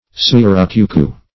Surucucu \Su`ru*cu"cu\, n. (Zool.)